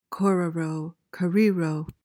PRONUNCIATION:
(KOR-uh-roh, kuh-REE-roh)